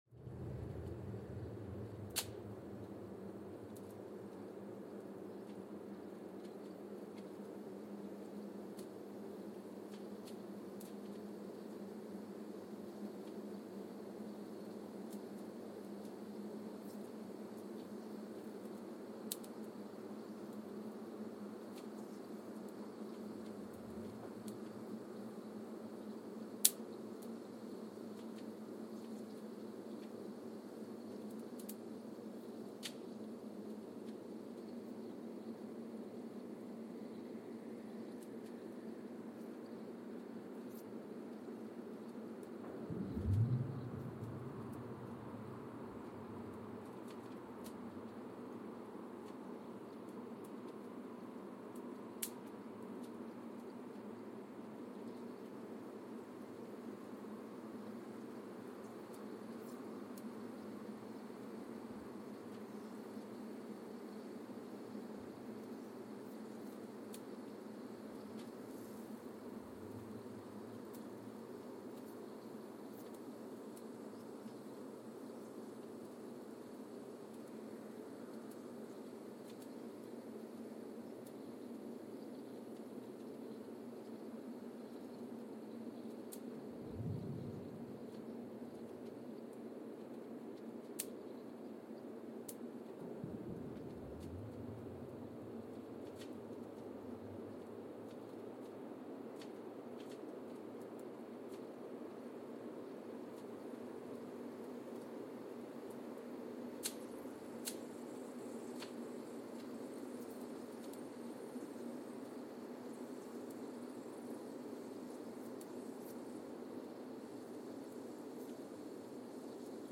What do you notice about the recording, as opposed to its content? Sensor : Geotech KS54000 triaxial broadband borehole seismometer Recorder : Quanterra Q330 @ 100 Hz Speedup : ×1,800 (transposed up about 11 octaves) Loop duration (audio) : 05:36 (stereo) SoX post-processing : highpass -2 90 highpass -2 90